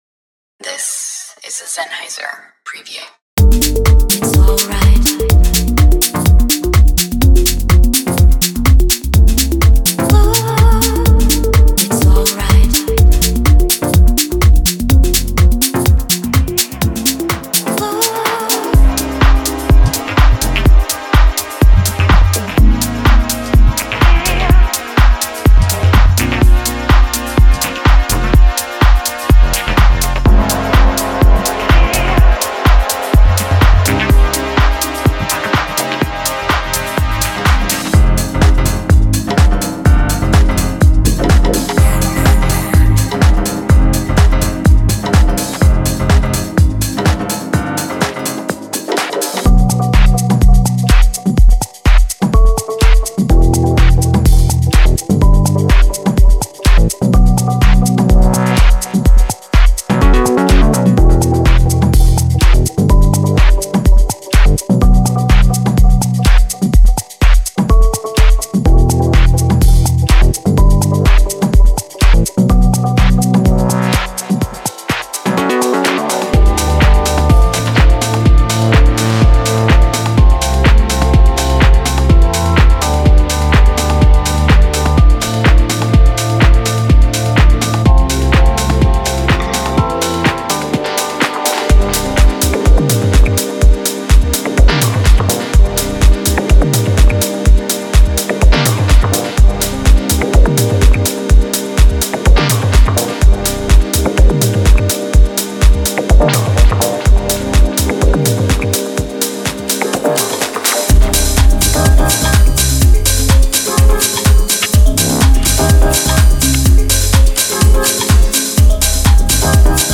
Genre:House
デモサウンドはコチラ↓